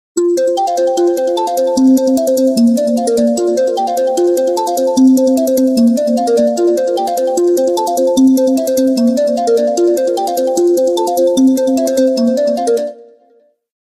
Categoria Sveglia